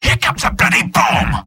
Robot-filtered lines from MvM. This is an audio clip from the game Team Fortress 2 .
{{AudioTF2}} Category:Sniper Robot audio responses You cannot overwrite this file.